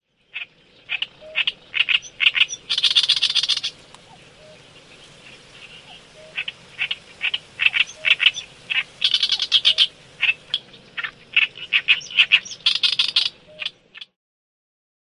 臺灣繁殖鳥類大調查 - 東方大葦鶯
Acrocephalus orientalis
宮城, 日本